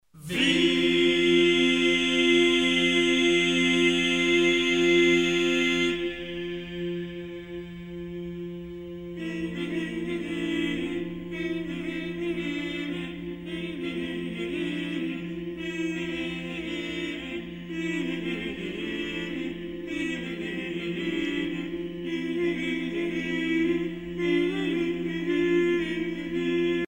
Organum 4 vocum
circonstance : dévotion, religion
Musique médiévale
Pièce musicale éditée